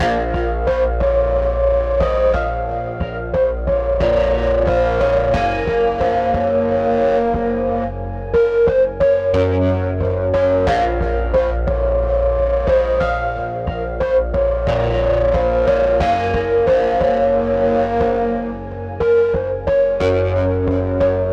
扭曲的吉他
描述：嘻哈吉他
Tag: 90 bpm Hip Hop Loops Guitar Electric Loops 3.59 MB wav Key : Unknown